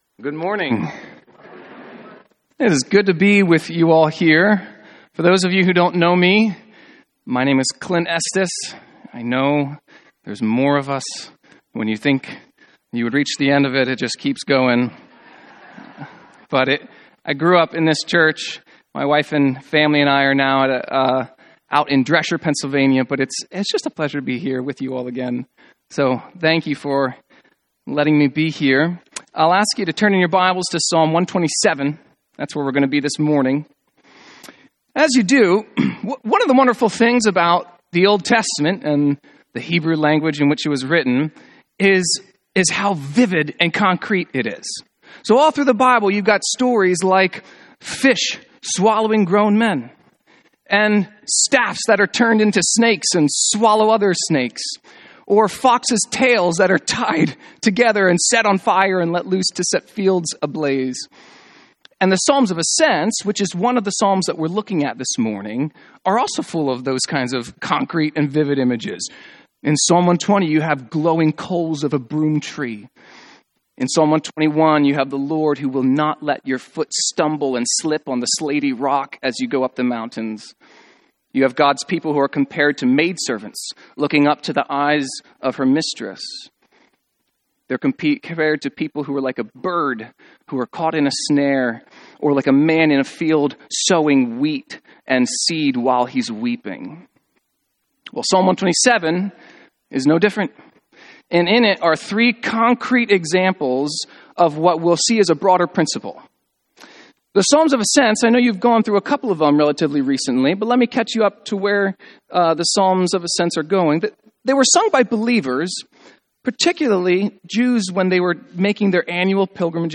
Sermons on Psalm 127 — Audio Sermons — Brick Lane Community Church